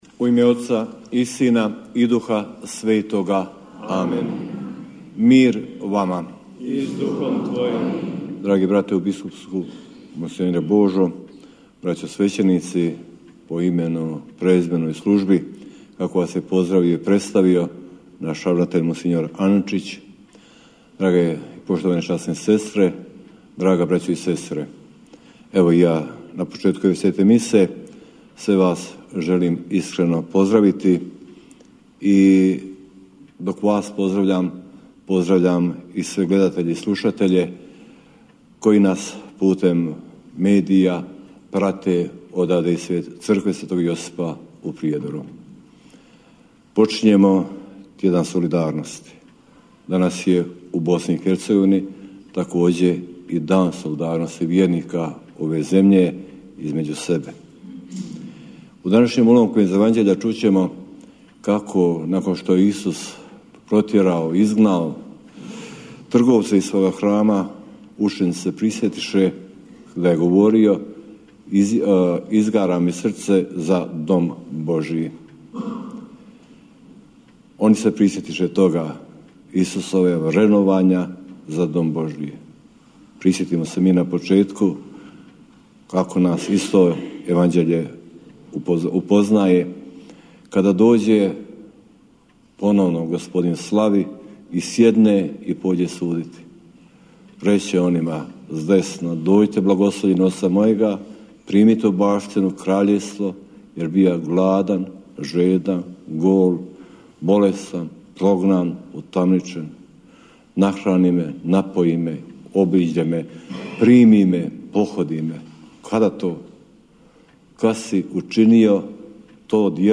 Euharistijsko slavlje u okviru Tjedna solidarnosti, koji se ove godine odvija pod motom „Solidarnost u deset riječi!“, izravno je prenosila Hrvatska Radiotelevizija na svom prvom kanalu, a izravni prijenos preuzimala je i RTV Herceg-Bosna.